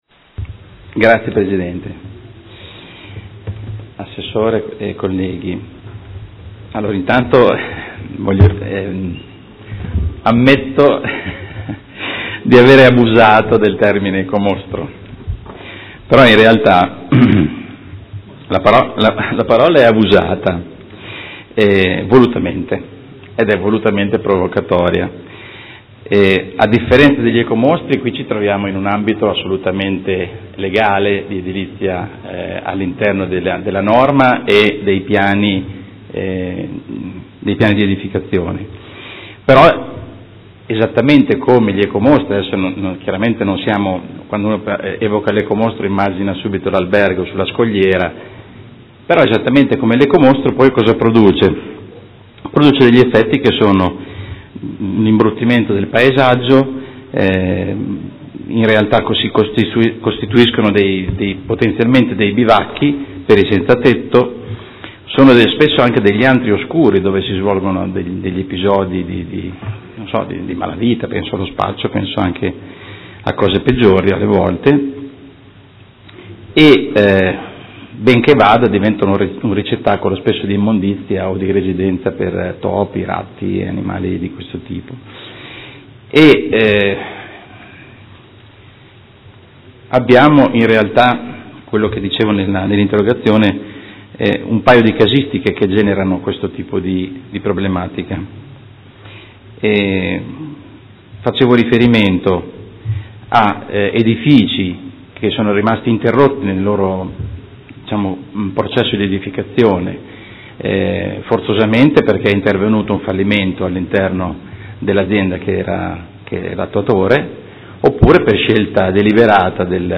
Carmelo De Lillo — Sito Audio Consiglio Comunale
Seduta del 9/06/2016 Interrogazione del Consigliere De Lillo (P.D.) avente per oggetto: Per qualche “ecomostro” in meno – Le opportunità per il completamento dei cantieri privati in stato di abbandono